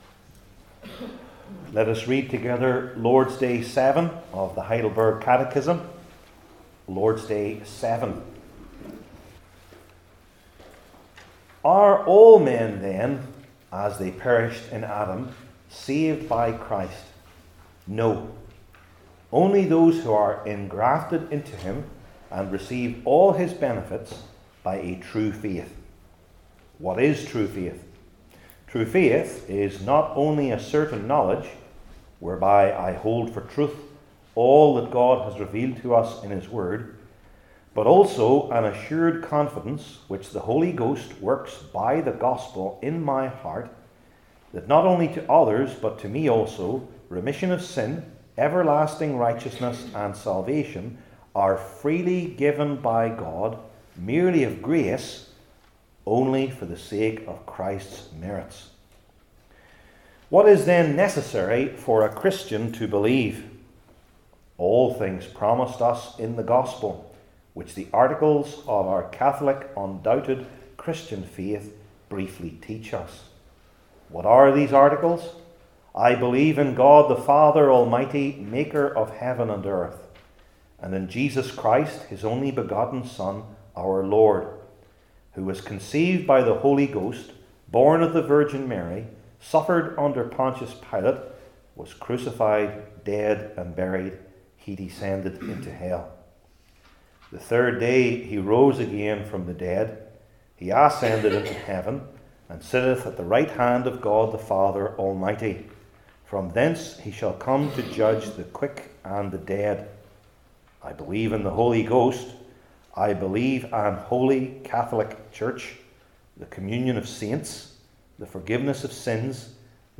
15:1-5 Service Type: Heidelberg Catechism Sermons I. The Terrible Arguments for It II.